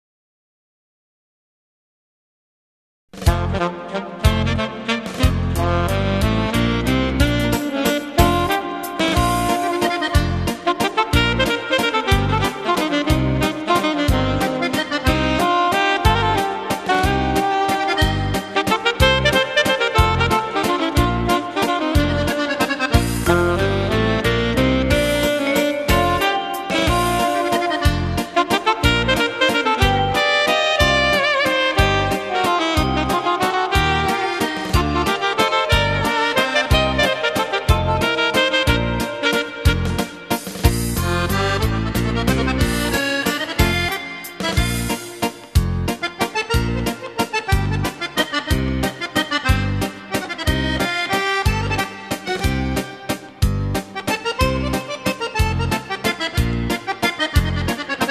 Valzer
12 brani per sax e orchestra.